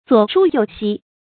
左書右息 注音： ㄗㄨㄛˇ ㄕㄨ ㄧㄡˋ ㄒㄧ 讀音讀法： 意思解釋： 喻倒行逆施。